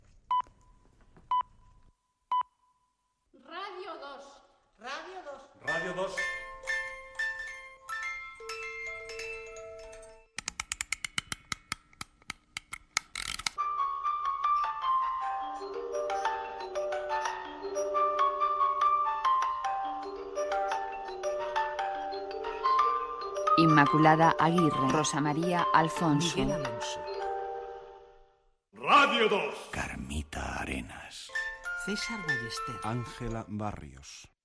Identificació de l'emissora